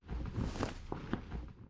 catch_air_3.ogg